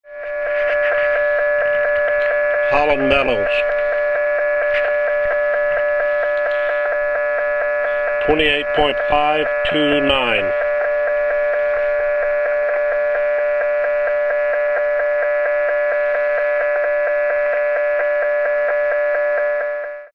The files in this section are recordings of RF noise from electric utilities, BPL and industrial equipment.
Noise Source: Broadband Power Line (BPL)
Where Found: Recordings were made in the 10 metre band. However, BPL generally covers much of the HF spectrum